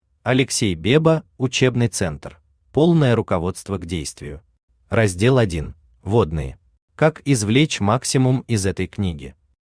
Аудиокнига Учебный центр. Полное руководство к действию | Библиотека аудиокниг
Полное руководство к действию Автор Алексей Беба Читает аудиокнигу Авточтец ЛитРес.